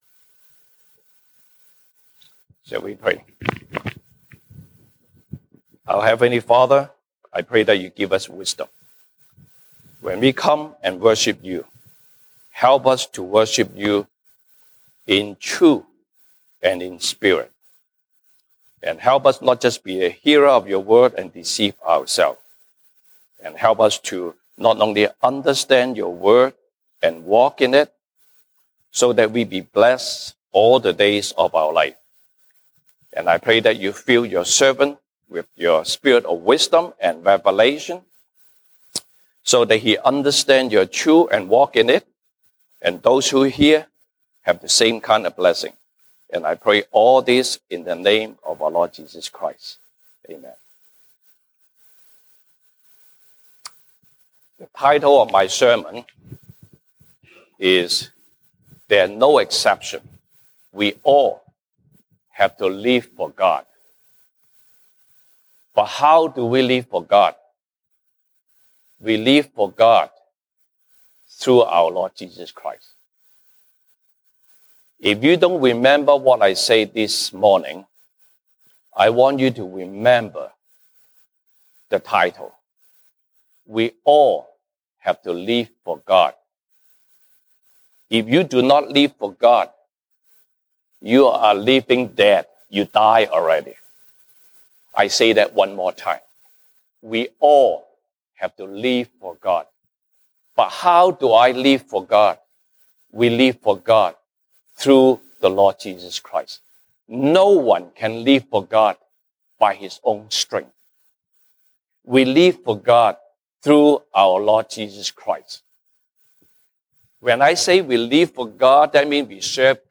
Sunday Service English